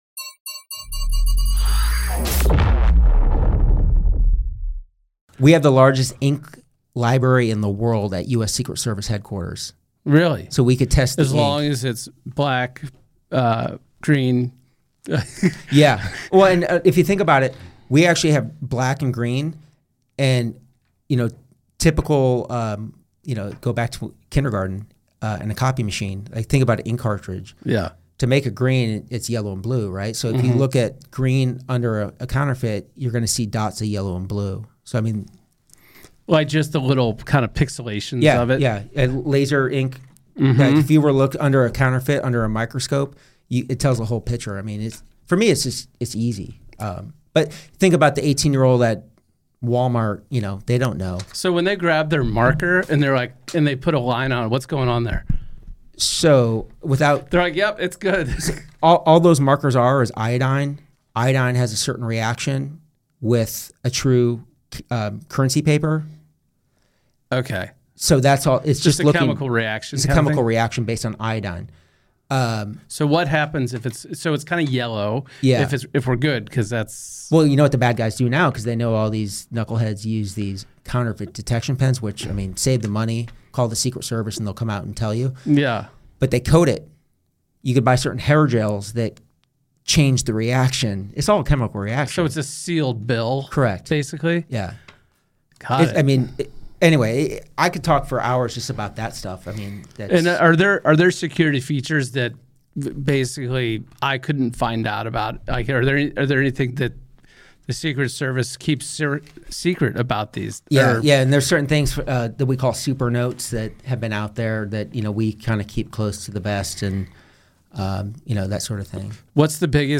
In Part 2 of our conversation